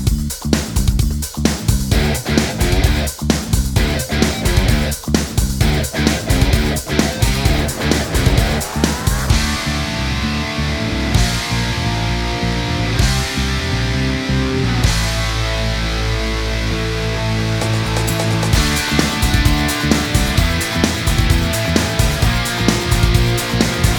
no Backing Vocals Indie / Alternative 5:01 Buy £1.50